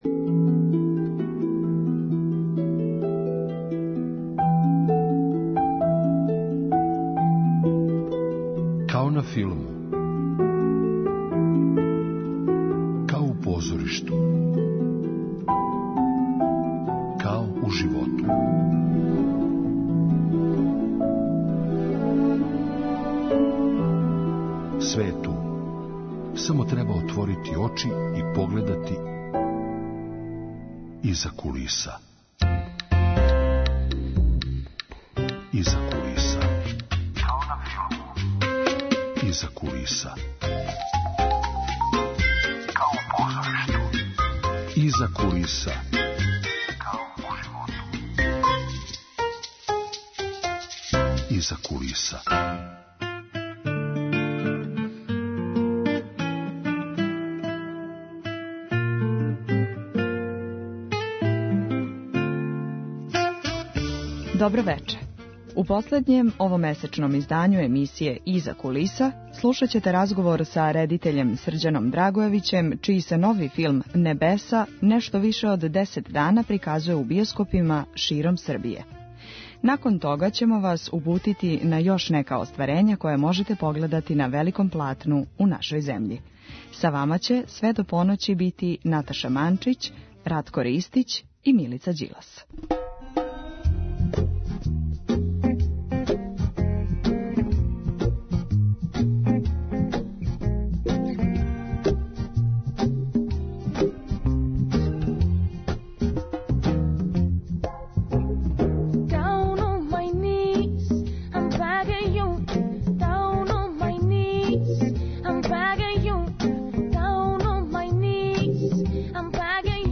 У последњем овомесечном издању емисије „Иза кулиса“ слушаћете разговор са редитељем Срђаном Драгојевићем.